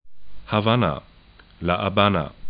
Havanna ha'vana